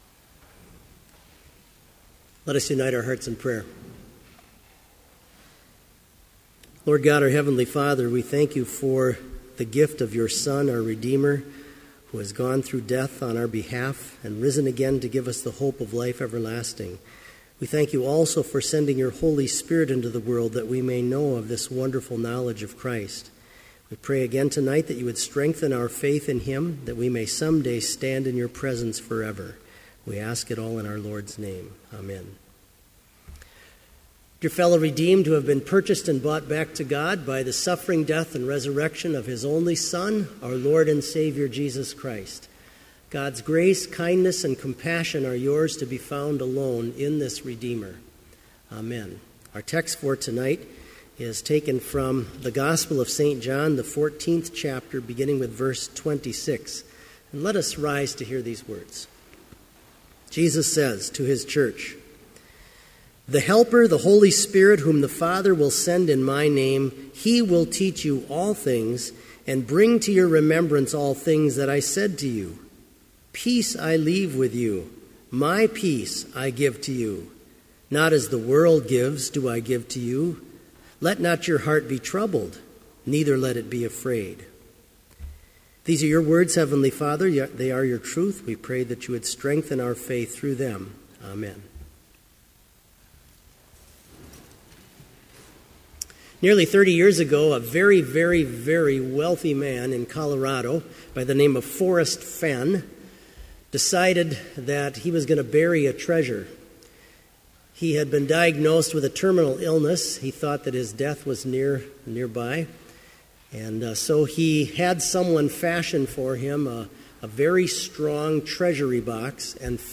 Sermon audio for Evening Vespers - May 6, 2015